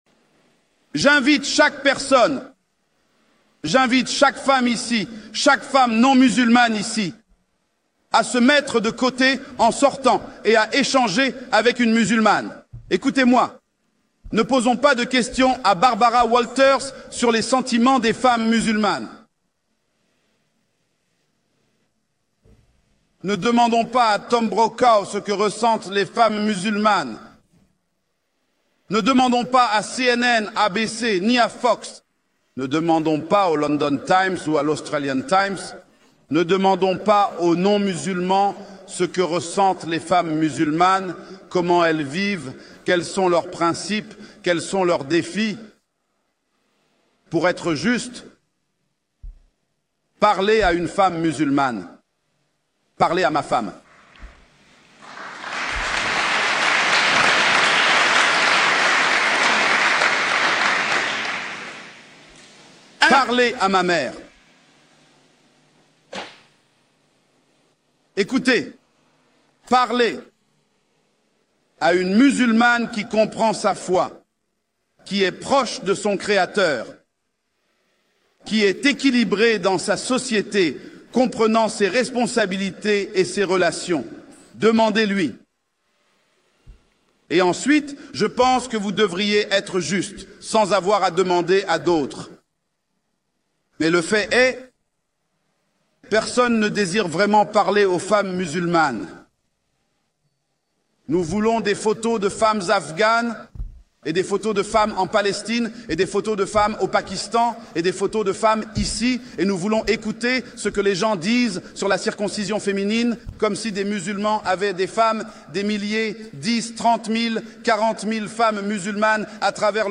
extrait d'une des conférences